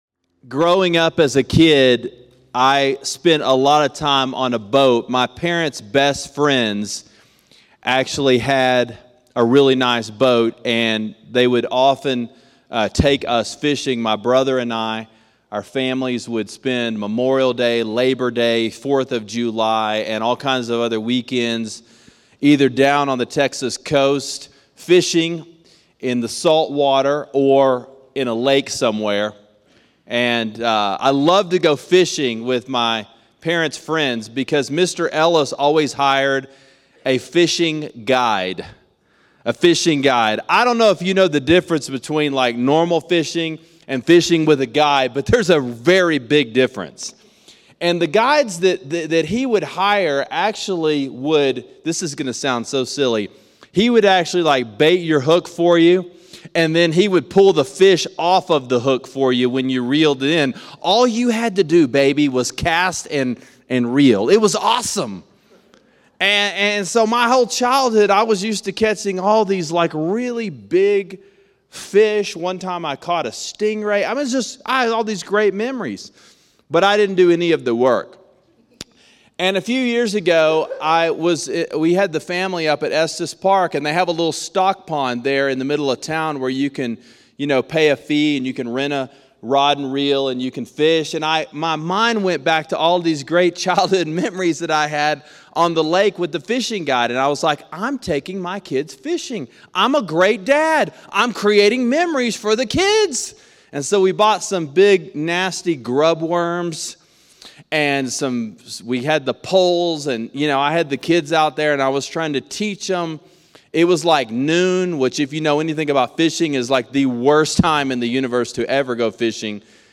Miracles: The Messages In The Miracle: Luke 5:1-11 – Sermon Sidekick